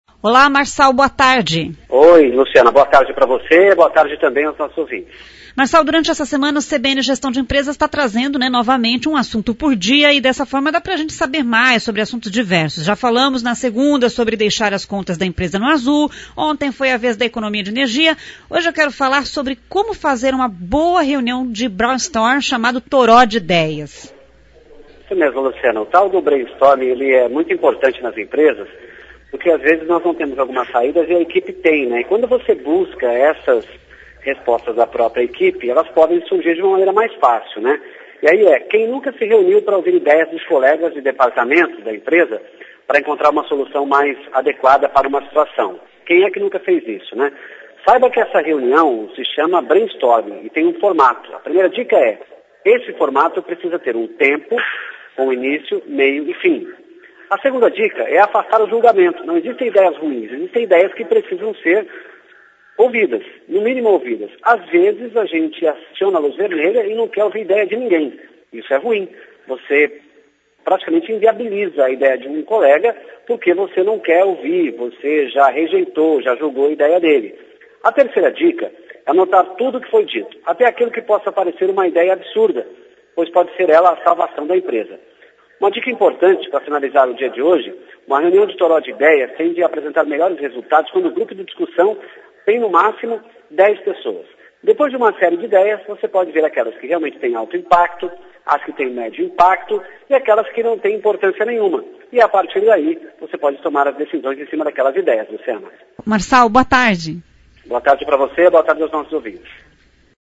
GESTAO EMPRESA-10-6-15 AO VIVO.mp3